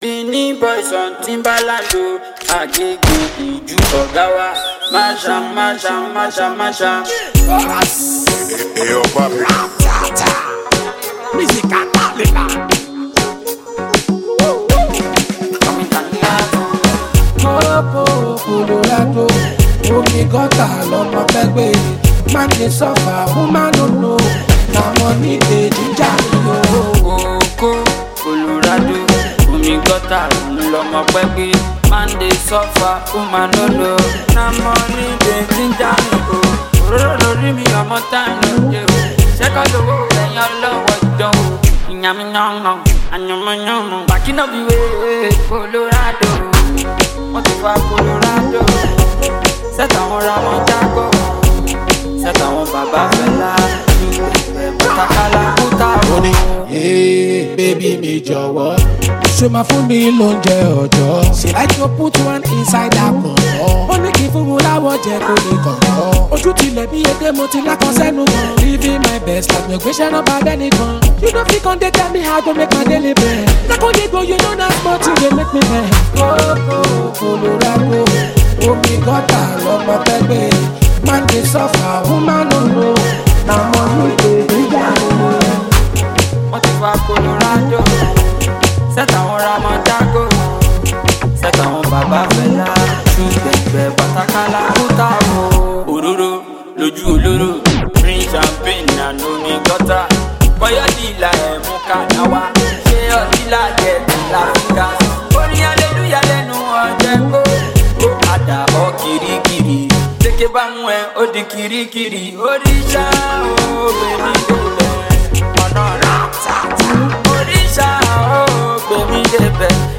Heavily skilled Nigerian street-hop singer and performer